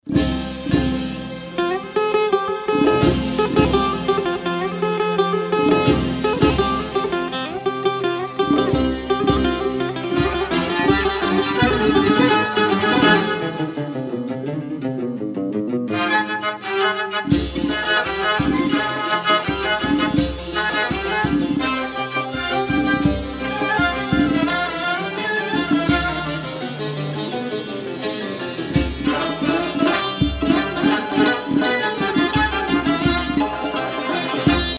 زنگ موبایل سنتی عاشقانه و ملایم بی کلام